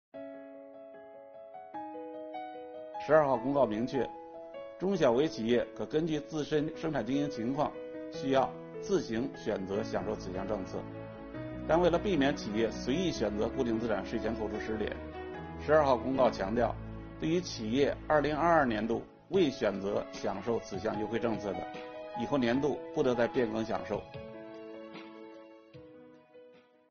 本期课程由国家税务总局所得税司一级巡视员刘宝柱担任主讲人，解读中小微企业购置设备器具按一定比例一次性税前扣除政策。